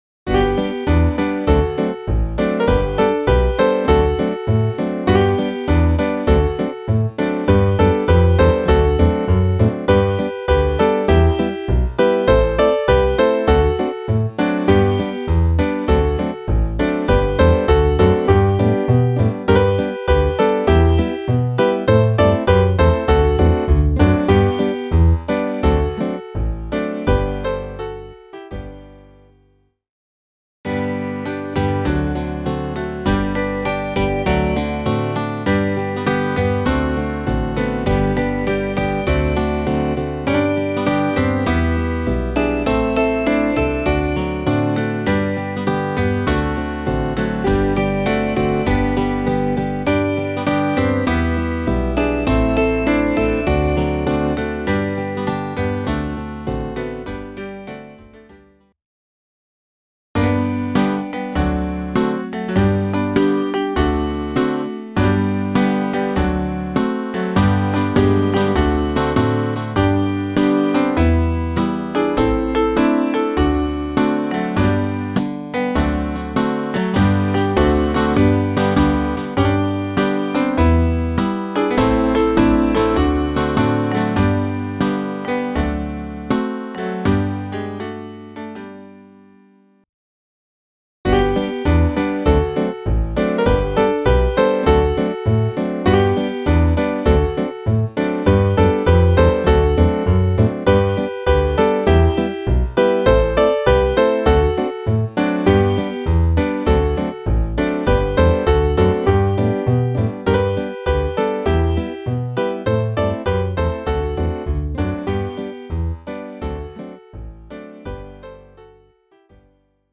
Variationen